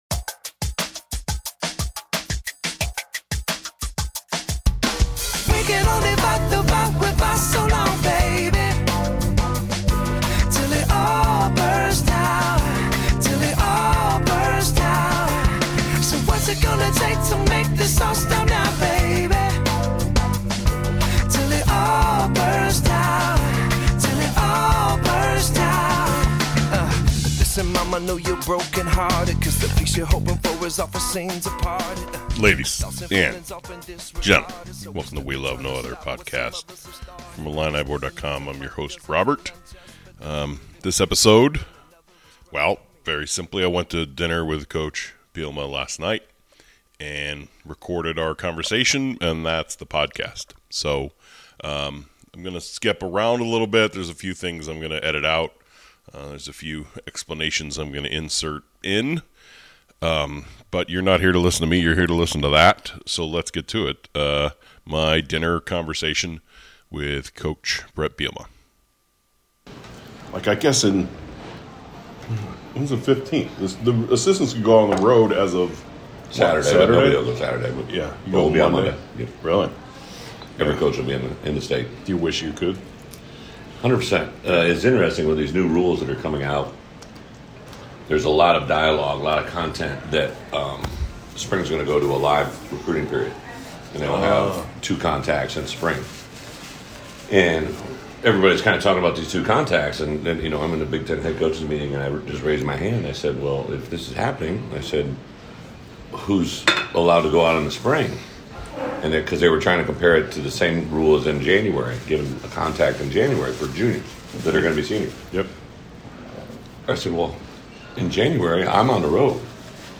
Coach Bielema and I went to dinner last night and talked football (and life). This is that conversation.